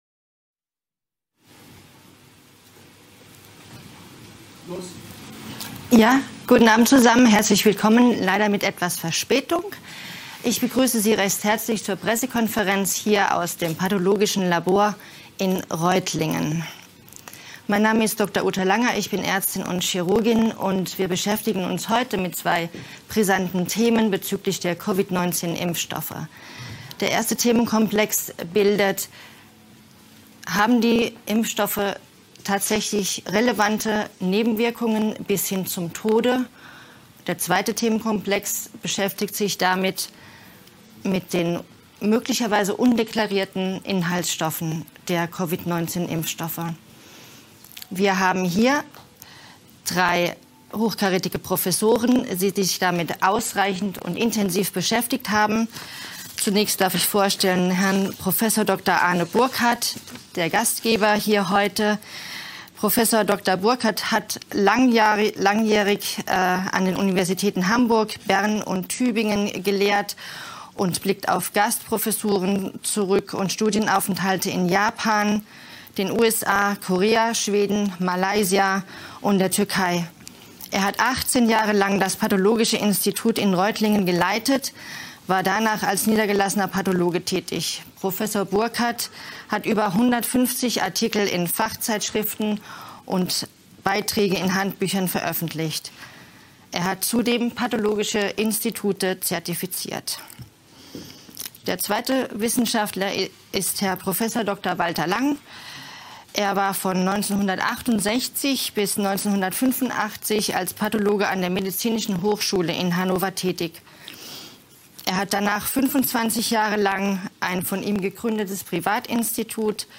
Pressekonferenz